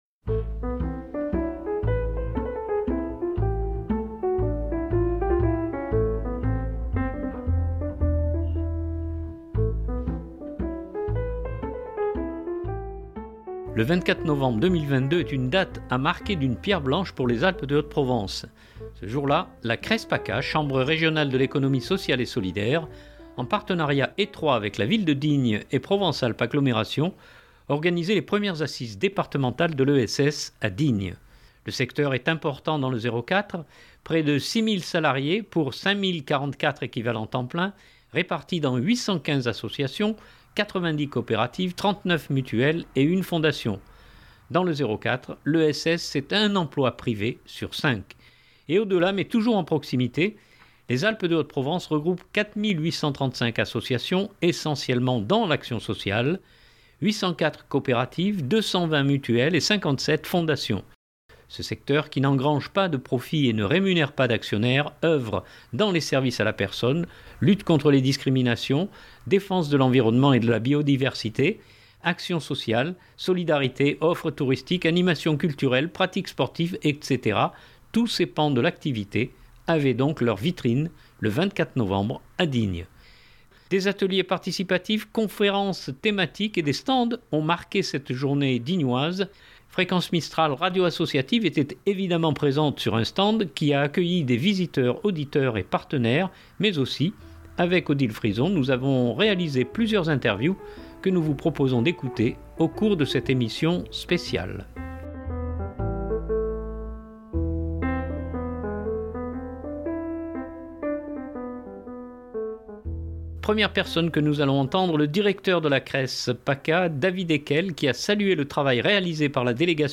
Des ateliers participatifs, conférences thématiques et des stands ont marqué cette journée dignoise.